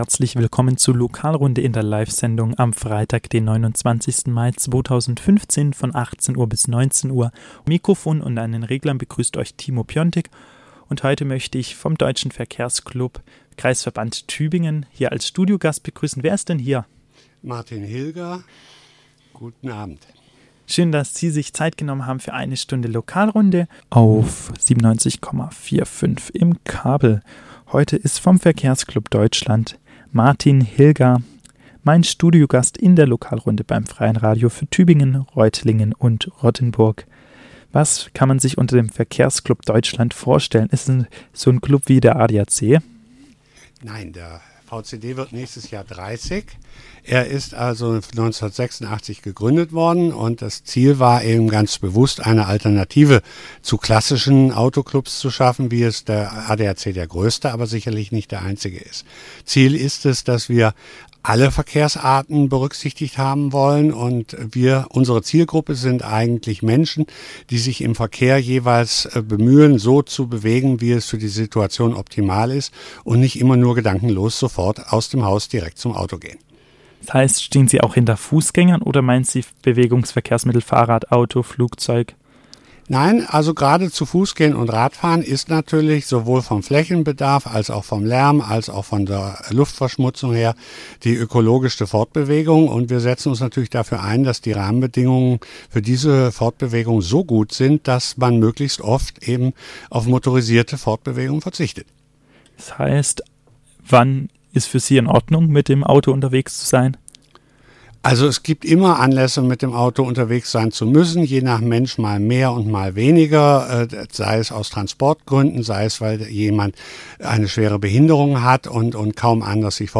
Wie dies gmeint ist erläuterte der Studiogast im Interview.